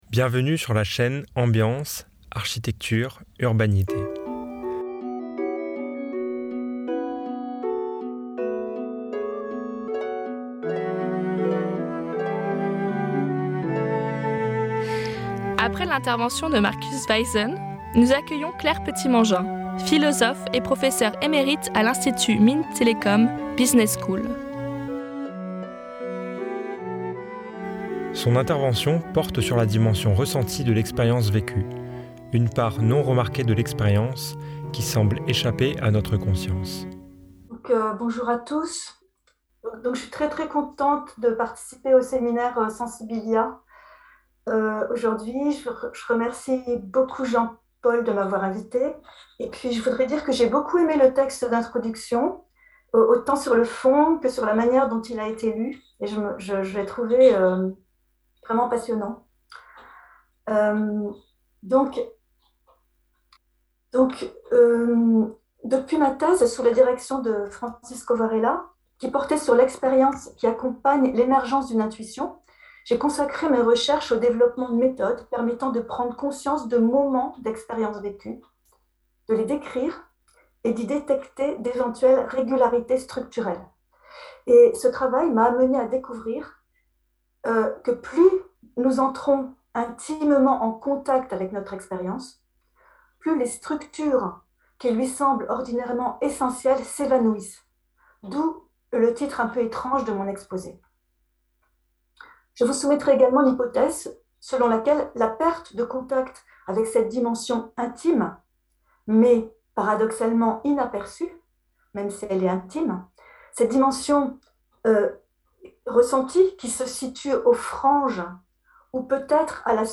Entre les mots et les choses : intervention